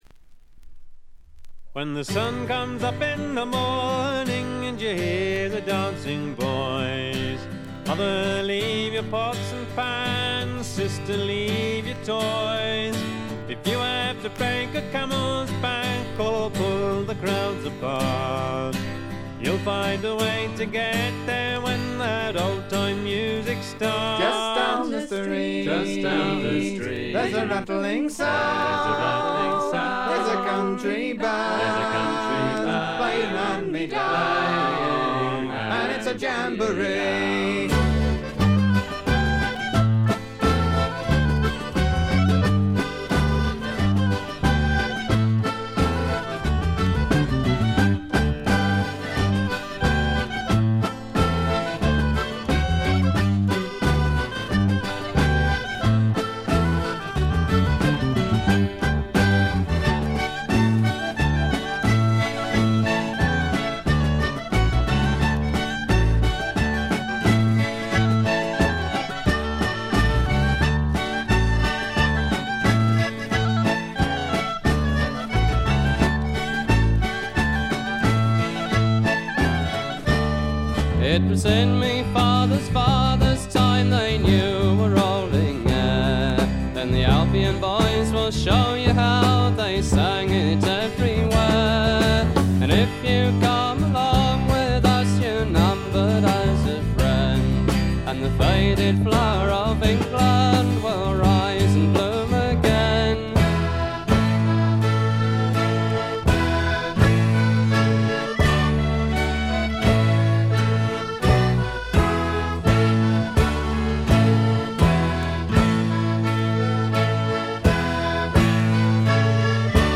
軽微なチリプチ程度。
エレクトリック・トラッドの基本中の基本です。
試聴曲は現品からの取り込み音源です。